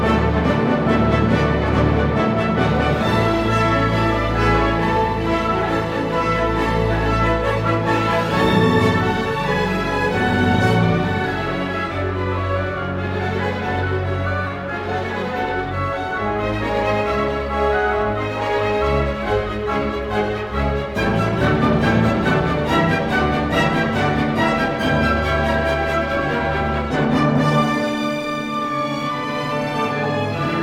0 => "Musique orchestrale"